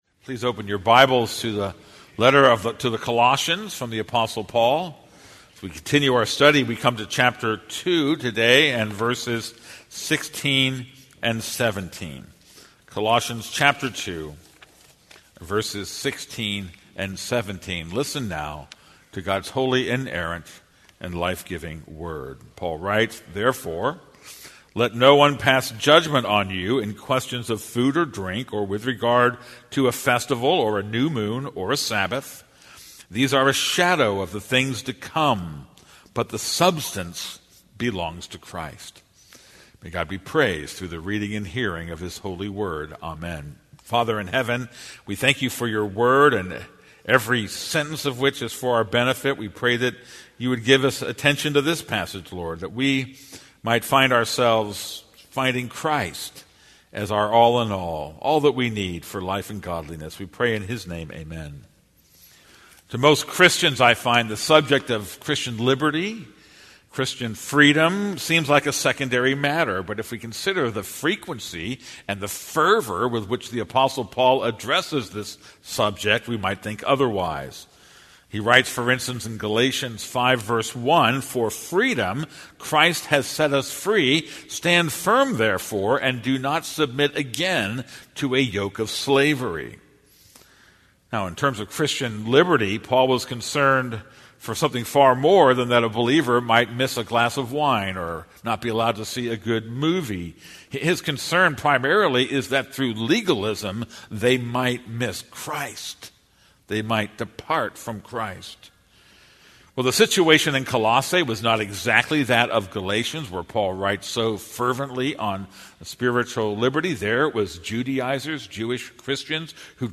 This is a sermon on Colossians 2:16-17.